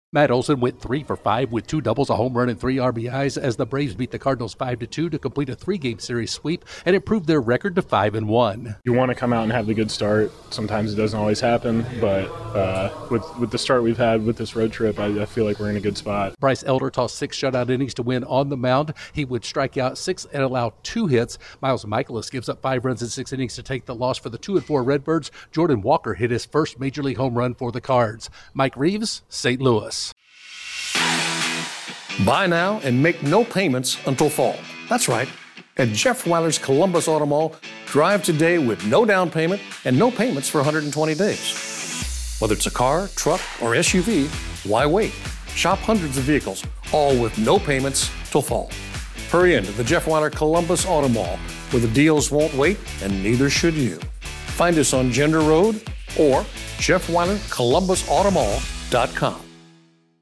The Braves sweep away the Cardinals. Correspond ent